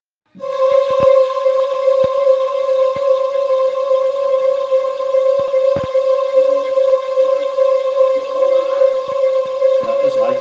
Die Schwebung
Schwebungsphänomen (Signal mit periodisch schwankender Lautstärke) leicht verständlich.
Schwebung.opus